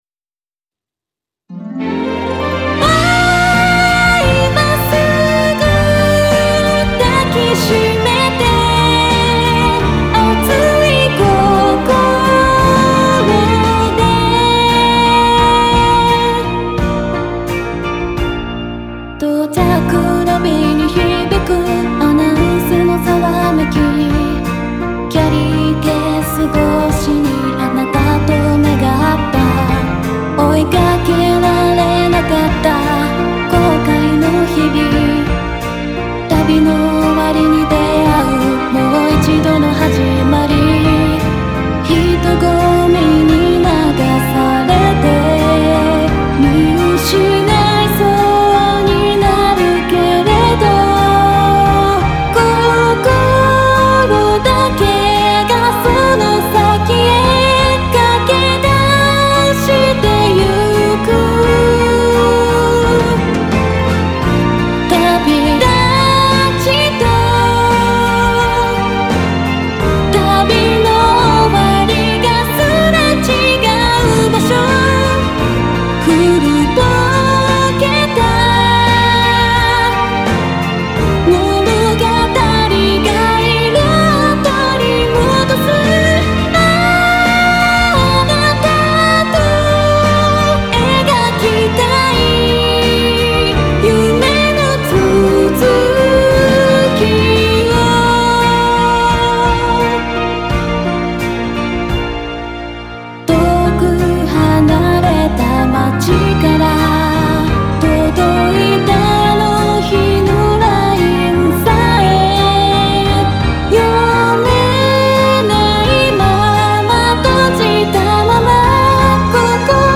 ・Synthesizer V Studio 2 PRO「Mai 2」